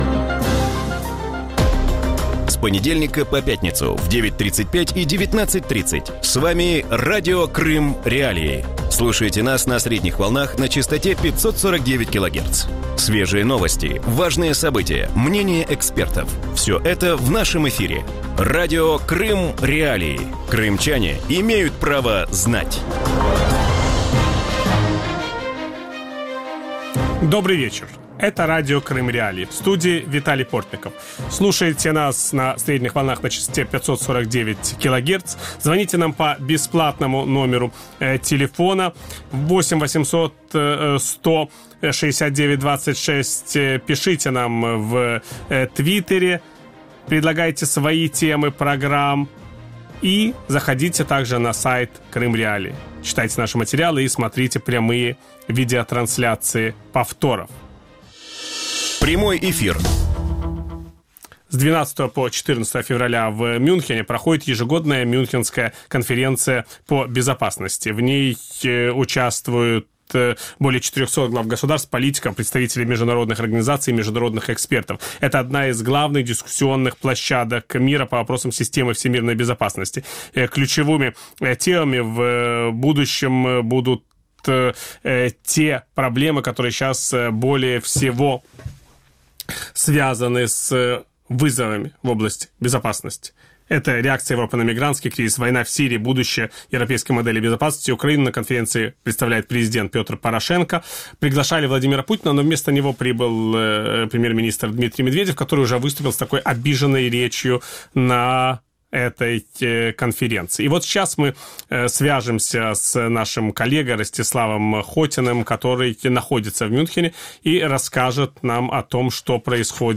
В вечернем эфире Радио Крым.Реалии обсуждают события на Мюнхенской конференции по безопасности.